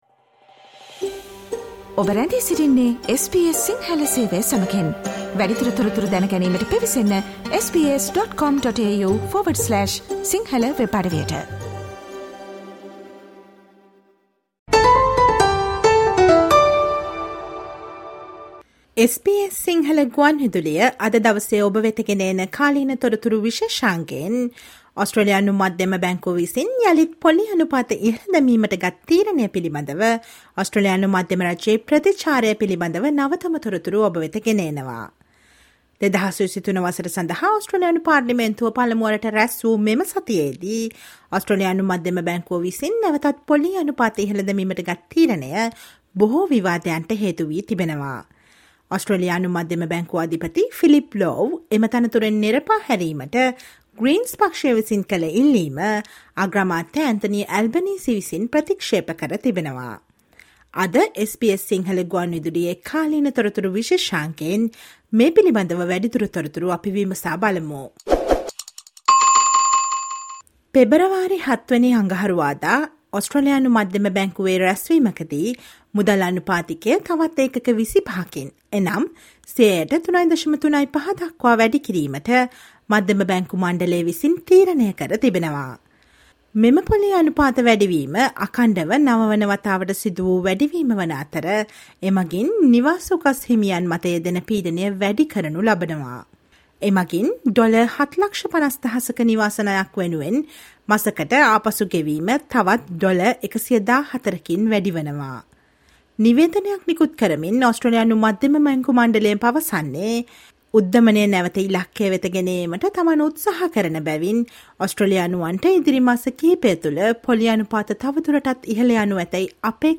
Listen to the SBS Sinhala radio current affair feature on Australian government's respond over RBA interest rate lift including opposition's criticism and calls to sack the RBA governor.